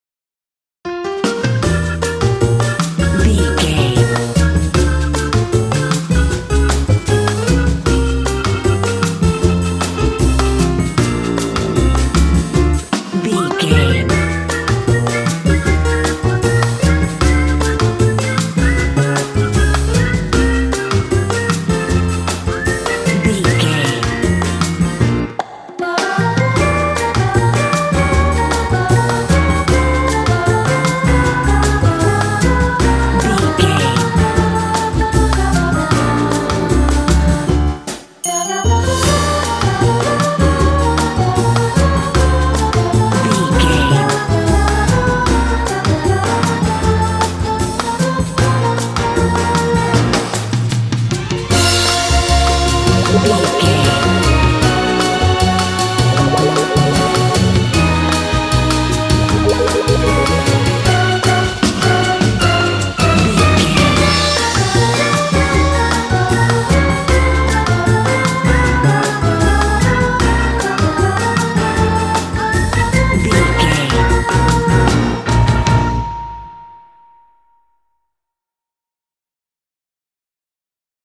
Uplifting
Aeolian/Minor
percussion
flutes
piano
orchestra
double bass
silly
circus
goofy
comical
cheerful
perky
Light hearted
quirky